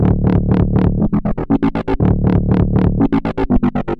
描述：八分音符和十六分音符的摇摆和超速组合的摇摆低音。Dubstep低音摇摆
Tag: 120 bpm Dubstep Loops Bass Wobble Loops 689.11 KB wav Key : C